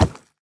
Minecraft Version Minecraft Version snapshot Latest Release | Latest Snapshot snapshot / assets / minecraft / sounds / mob / piglin / step1.ogg Compare With Compare With Latest Release | Latest Snapshot
step1.ogg